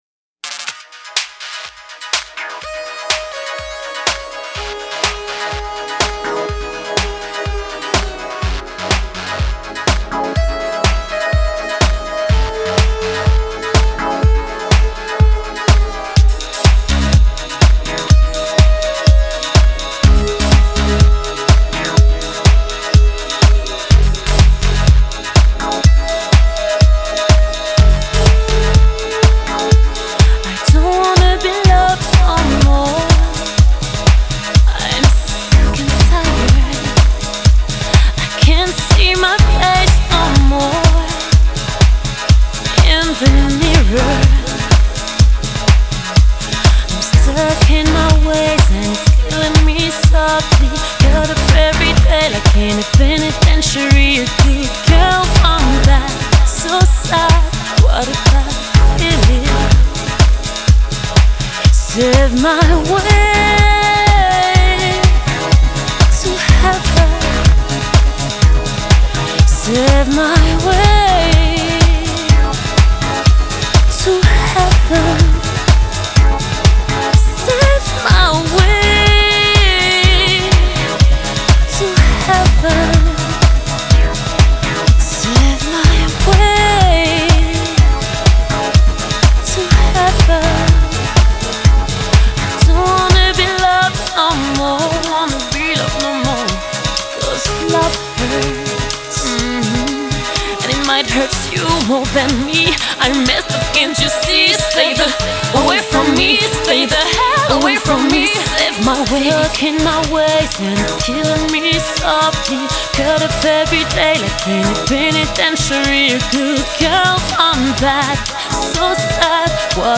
Genre: Pop, Dance, R&B, Soul, French